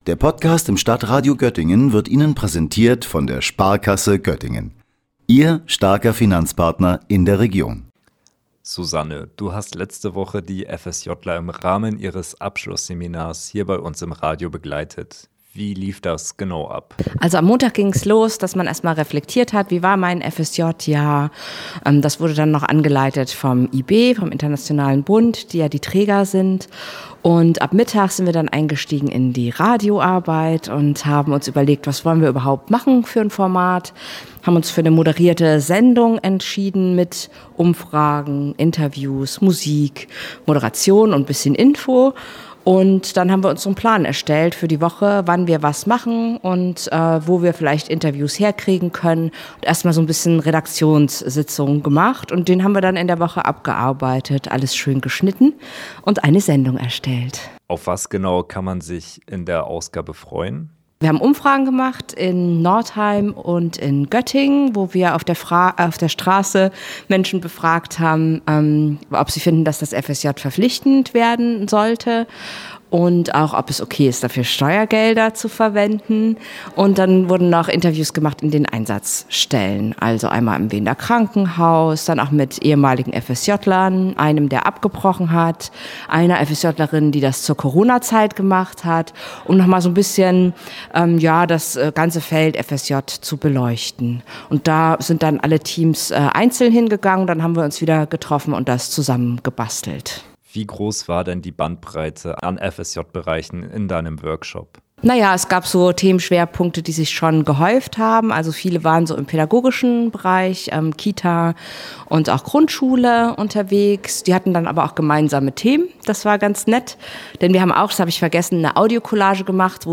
Am vergangenen Freitag ging das Freiwillige Soziale Jahr bzw. der Bundesfreiwilligendienst für rund 200 Menschen in Göttingen zu Ende. In der letzten Woche des Projekts haben die Freiwilligen Workshops besucht, um ihr Jahr Revue passieren zu lassen. Eines davon hat auch bei uns im Stadtradio statt gefunden.